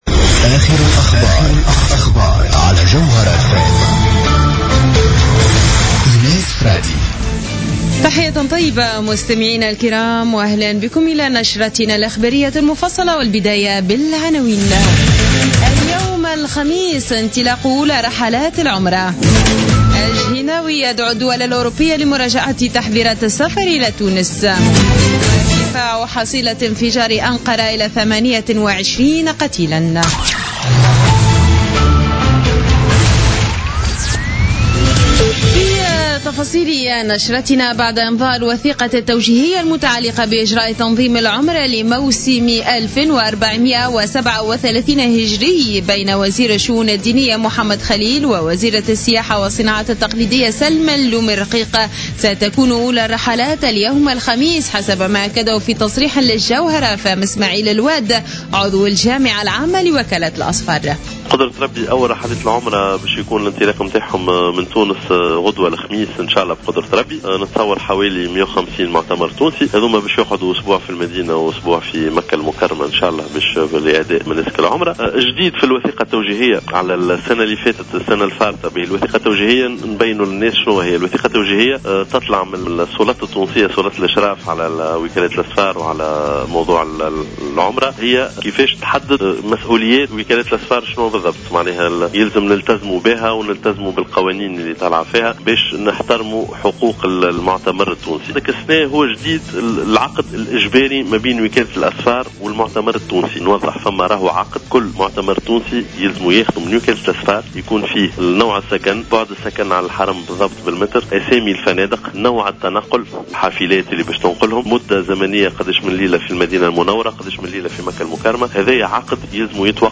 نشرة أخبار منتصف الليل ليوم الخميس 18 فيفري 2016